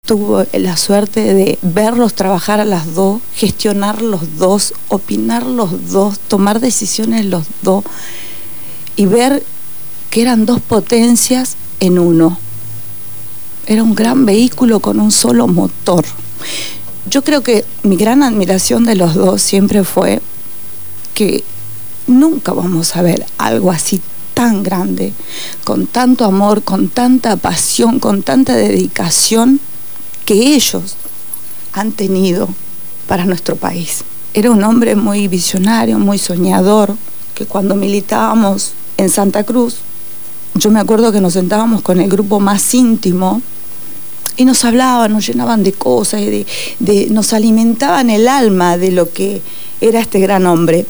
Estuvieron en los estudios de Radio Gráfica FM 89.3 durante el programa «Punto de partida» y nos conducieron por un relato desde las primeras internas que culminan llevándolo a la Intendencia de Río Gallegos, las anécdotas, las dificultades en la función de gobierno, sus sueños y las horas interminables de trabajo desde la visión de dos militantes de la primera hora.
En esta entrevista los describe y lo recuerda con emoción.